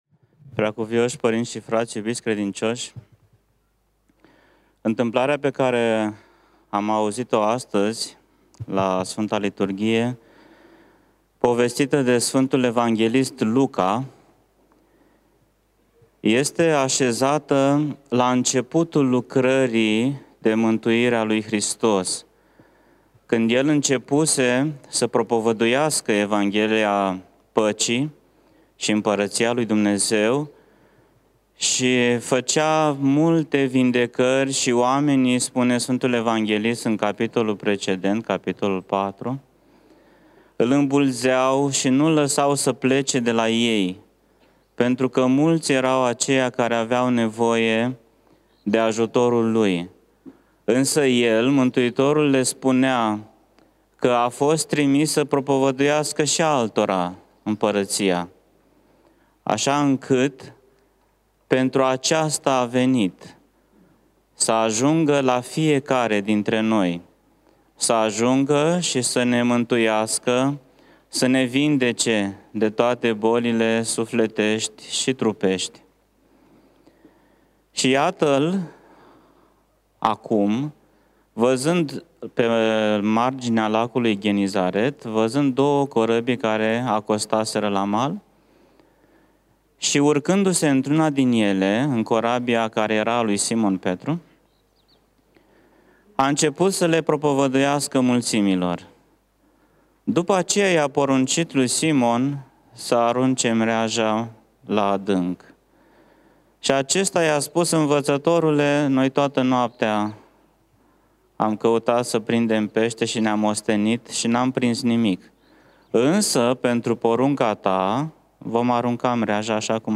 Predică PF Daniel